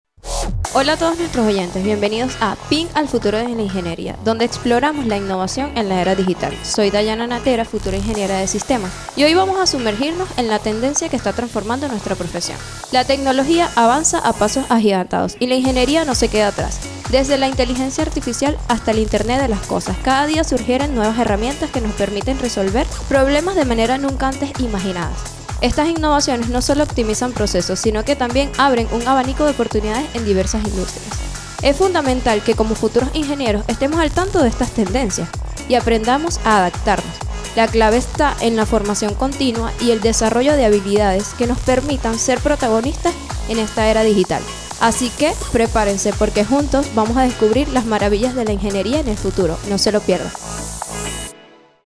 Estudiantes de la carrera de Ingeniería de Sistemas